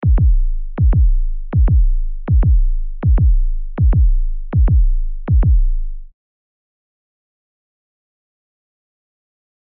دانلود آهنگ ضربان قلب از افکت صوتی انسان و موجودات زنده
جلوه های صوتی
دانلود صدای ضربان قلب از ساعد نیوز با لینک مستقیم و کیفیت بالا